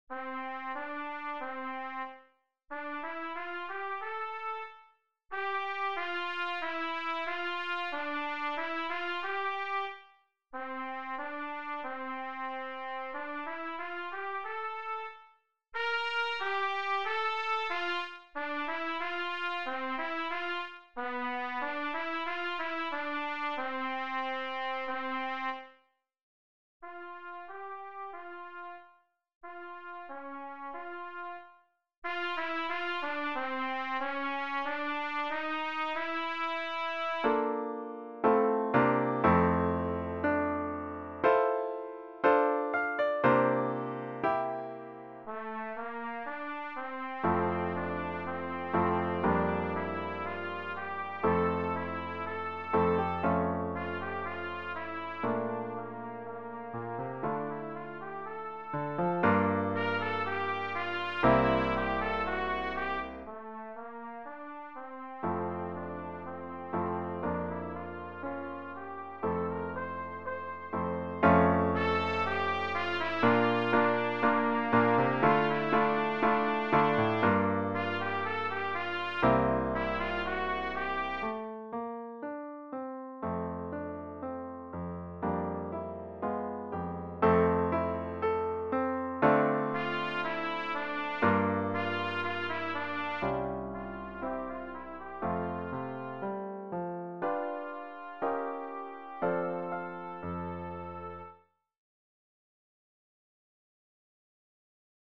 Pour trompette (ou cornet) et piano DEGRE FIN DE CYCLE 1